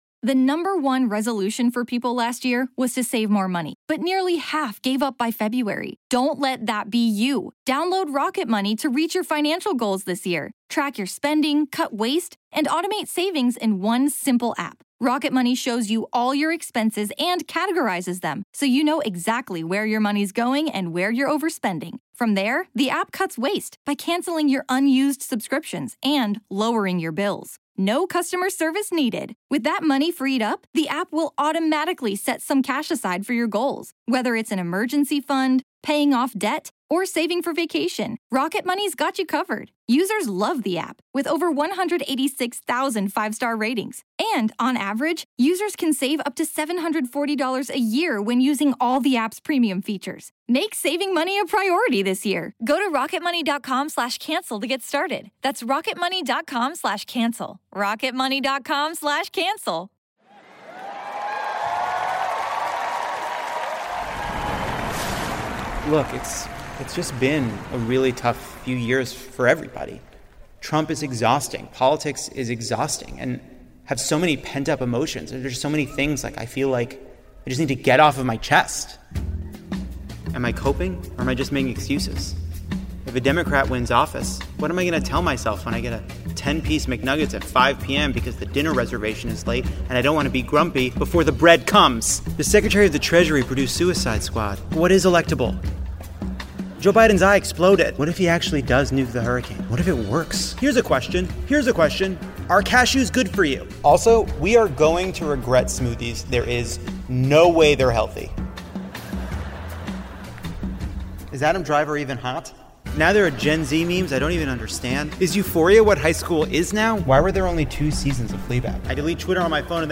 Live from Radio City!